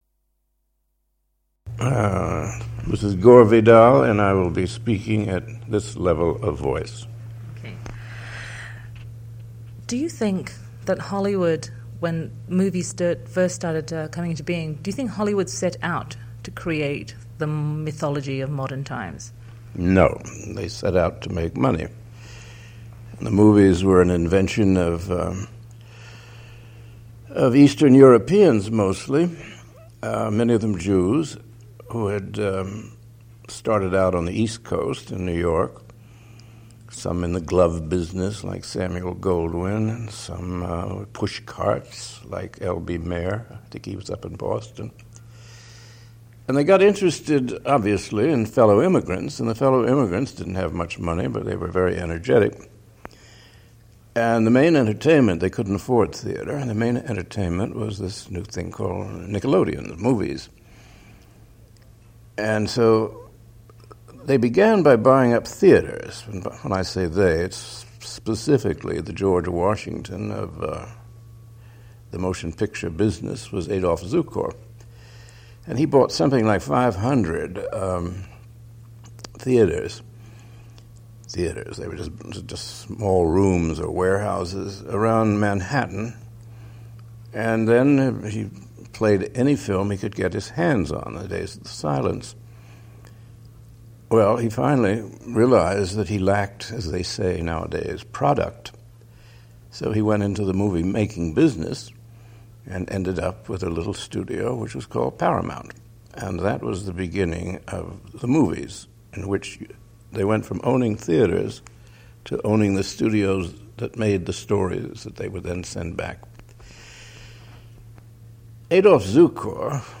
in this unedited interview